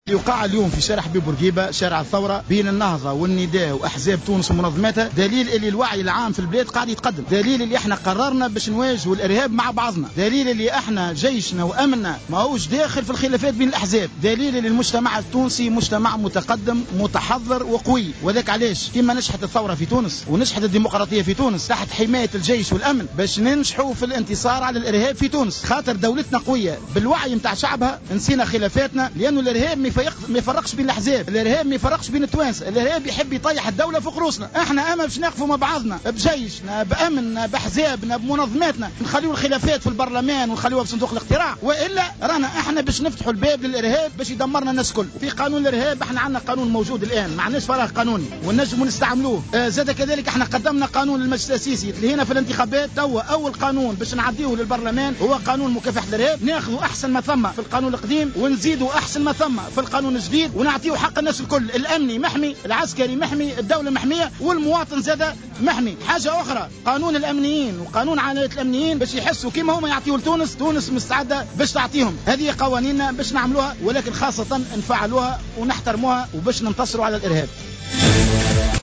وقد اكد القيّادي في حركة النهضة عبد الكريّم الهاروني أن هذه المسيرة الوطنية تجسد معاني الوحدة الوطنية في مكافحة الإرهاب، مبينا أن الإرهاب لا يفرق بين الأحزاب السياسية وبين التونسييّن ولا يمكنه استهداف وحدة الشعب التونسي مشيرا إلى أن المجتمع التونسي قوي ومتحضر ومتقدم حسب تعبيره. وأضاف الهاروني أن تونس ستنجح في القضاء على هذه الآفة بفضل وعي شعبها، مثل ما نجحت في الاستحقاق الانتخابي وفي إرساء الديمقراطية، داعيا إلى ترك الخلافات الحزبية جانبا والتوحد في مواجهة الإرهاب.